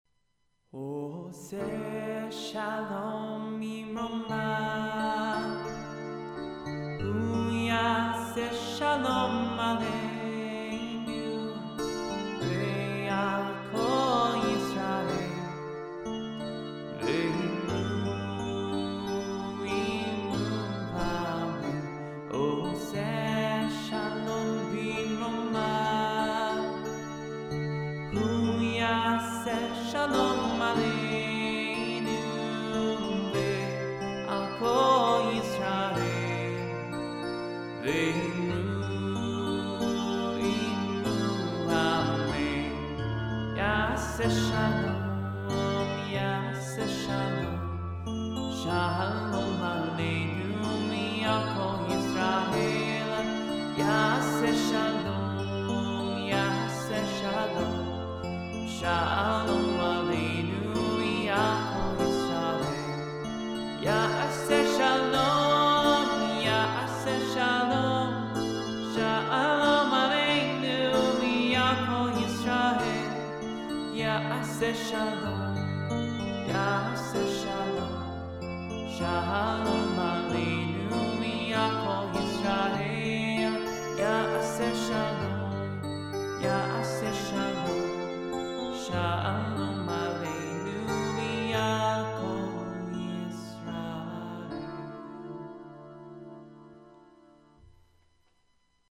Lullabies